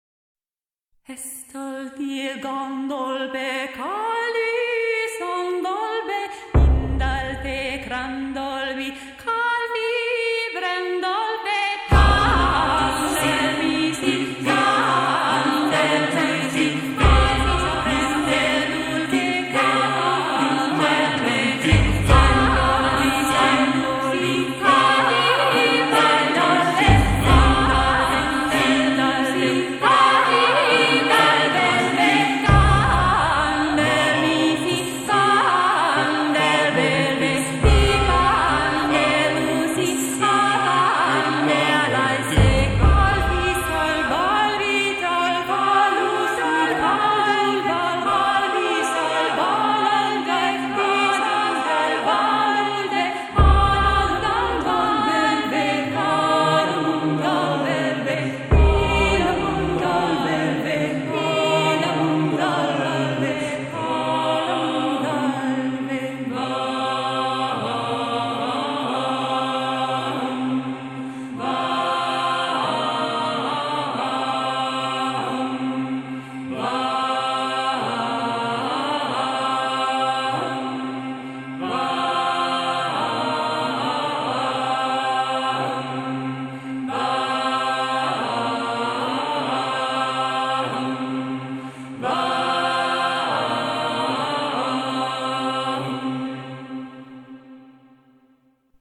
Ethereal Wave, Neoclassical Dark Wave, Folk